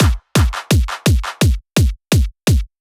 つまり、キックのリズムのちょうど真ん中にクラップが位置しています。
ゆっくりやると、「ドン、パン、ドン、パン、」と交互になりますね。